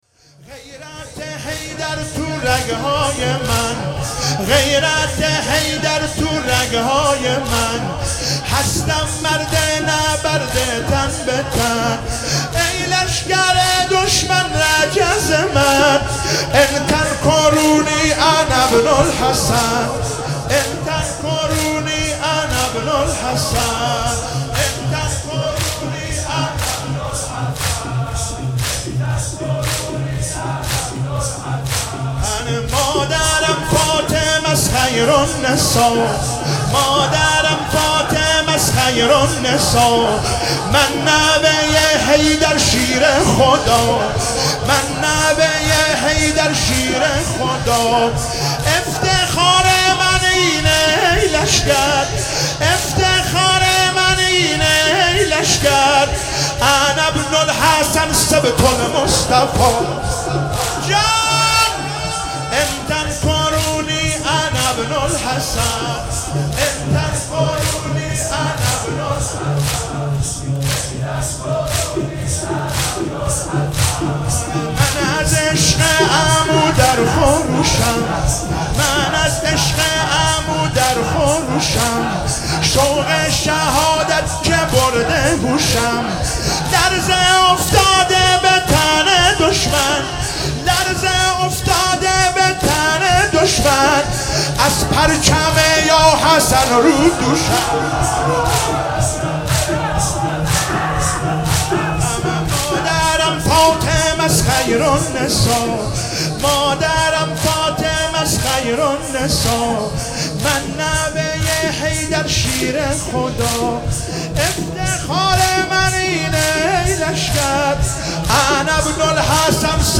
شب ششم محرم96 - زمینه - غیرت حیدر توی رگهای من